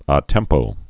(ä tĕmpō)